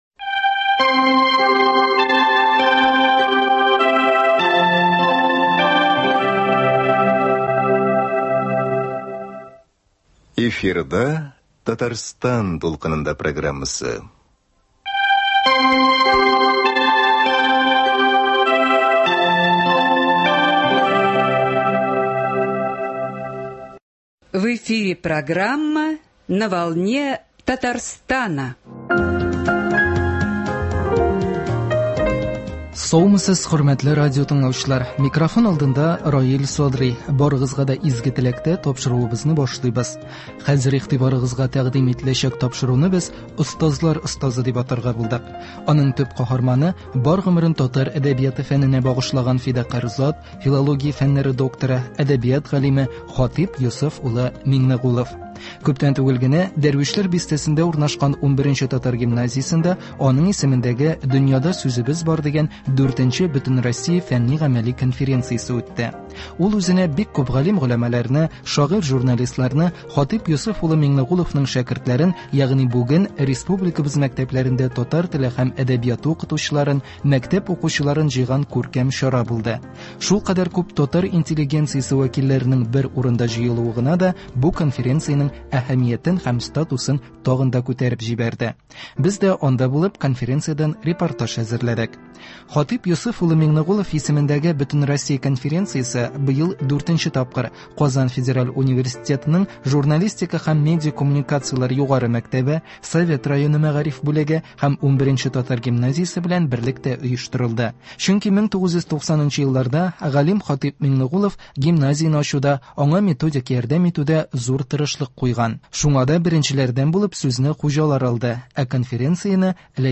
Хатыйп Миңнегулов исемендәге Бөтенроссия фәнни- гамәли конференциядән репортаж.